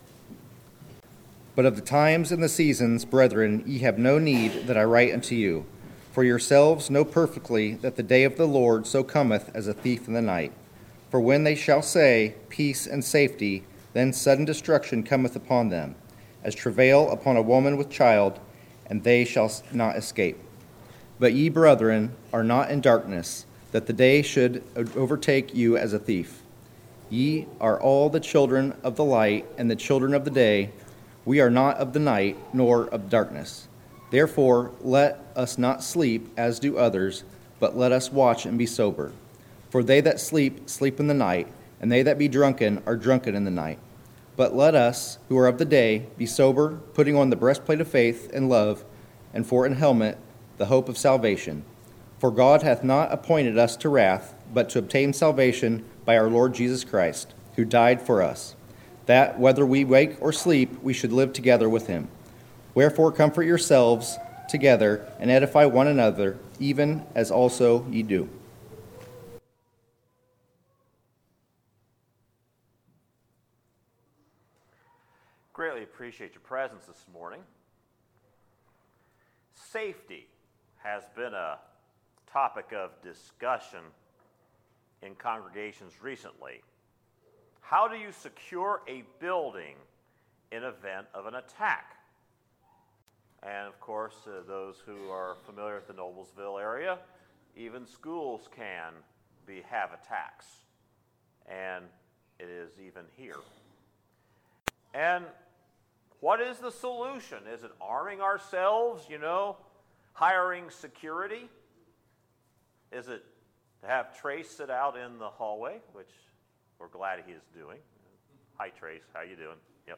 Sermons, August 26, 2018